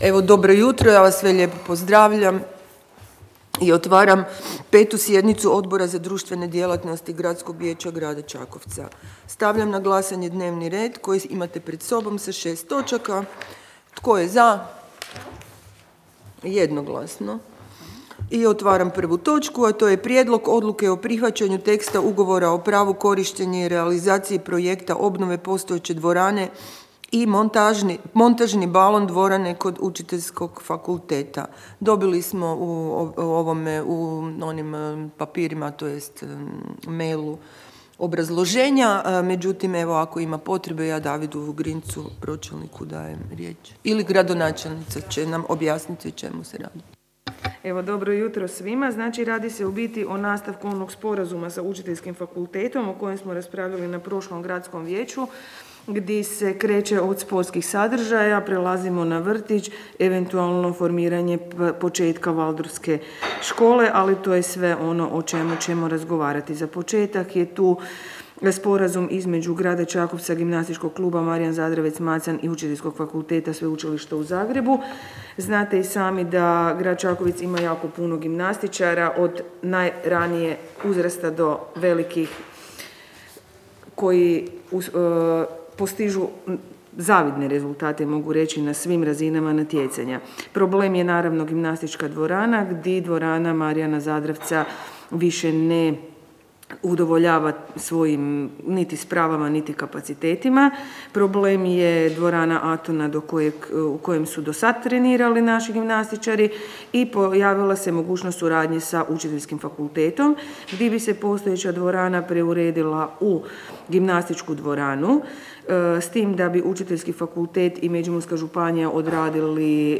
Obavještavam Vas da će se 5. sjednica Odbora za društvene djelatnosti Gradskog vijeća Grada Čakovca održati 24. siječnja 2022. (ponedjeljak), u 8.00 sati, u Upravi Grada Čakovca, u gradskoj vijećnici.